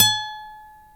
Index of /90_sSampleCDs/Roland L-CD701/GTR_Nylon String/GTR_Nylon Chorus
GTR NYLON20G.wav